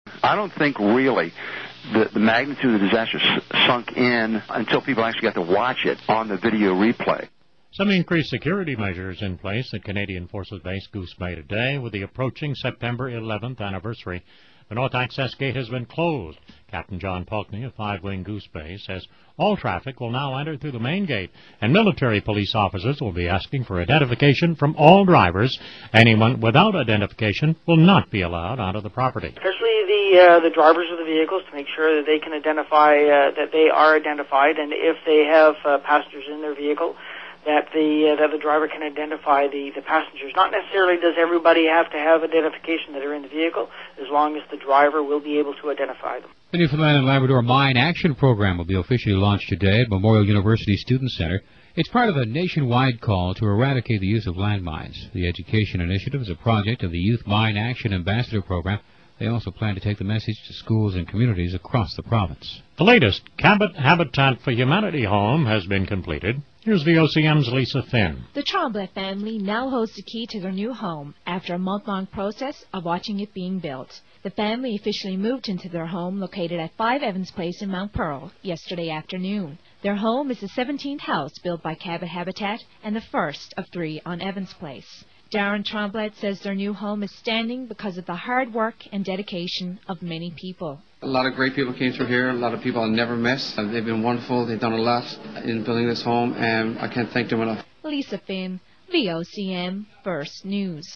Canadian English, Newfoundland
The maritime provinces of eastern Canada were settled much earlier than central and western Canada and they experienced migration from specific areas of the British Isles, notably south-west England and south-east Ireland. For this reason traditional speech in the area, especially in Newfoundland, is quite different from central Canada and much more reminiscent of regional English from the areas of the British Isles just mentioned.
Canada_Newfoundland_General.wav